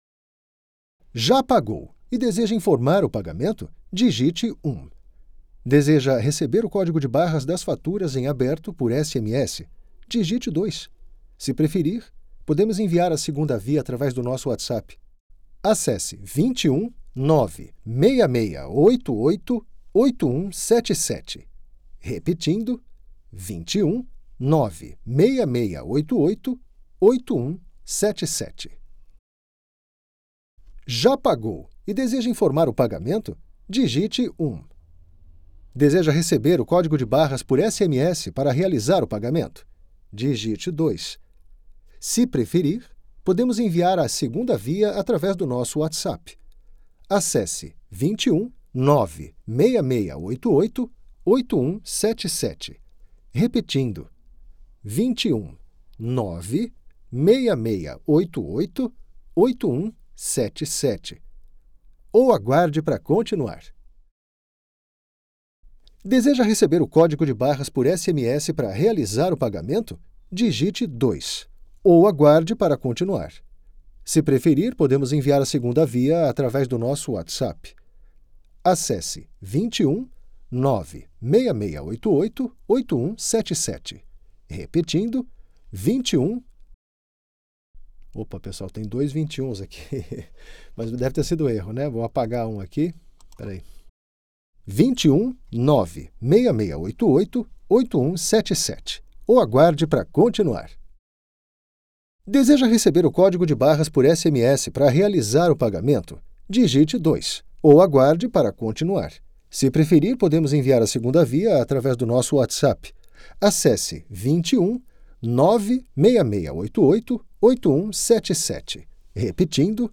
Naturelle, Amicale, Fiable, Corporative, Accessible
Téléphonie